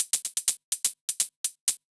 UHH_ElectroHatB_125-04.wav